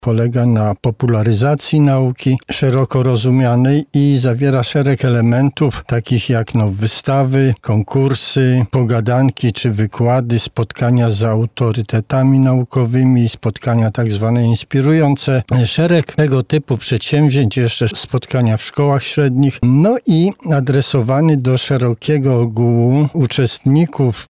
[PORANNA ROZMOWA] Wiedza wychodzi z sal wykładowych?